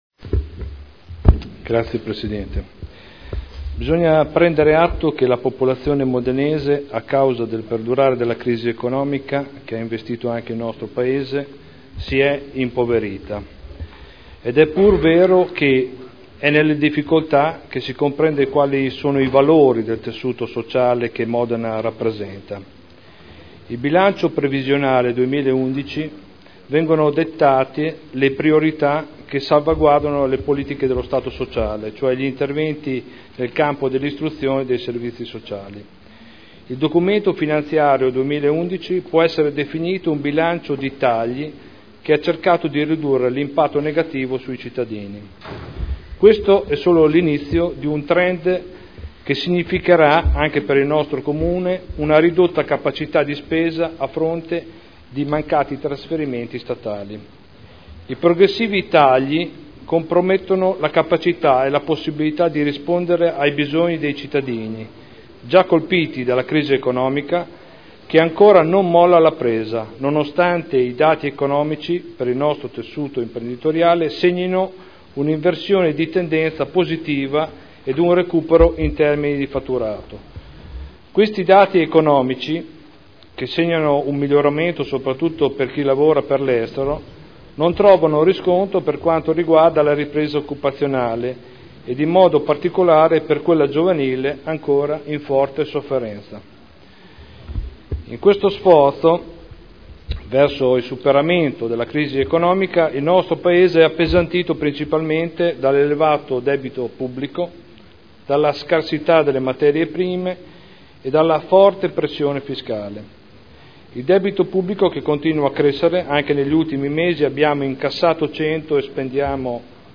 Seduta del 28/03/2011. Dibattito sul Bilancio.